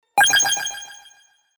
8ビットのサウンドが特有の雰囲気を醸し出しす音。